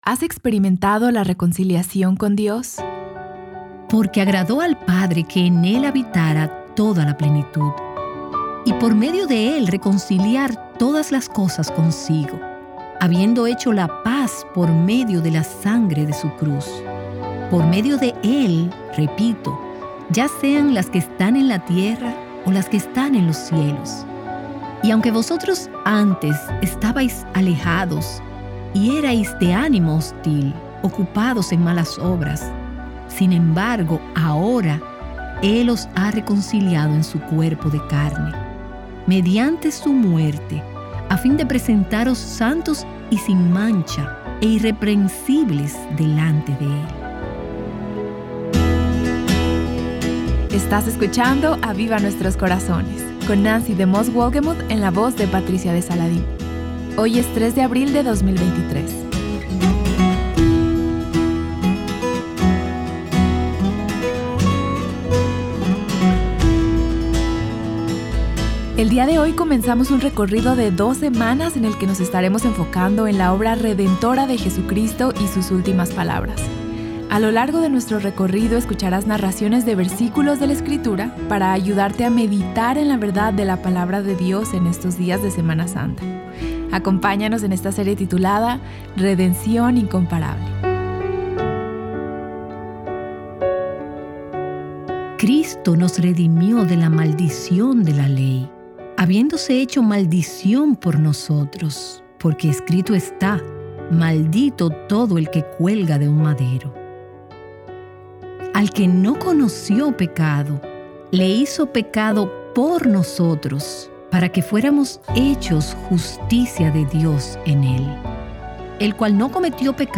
Escucharás narraciones de versículos de la Escritura que te ayudarán a meditar en la Palabra de Dios.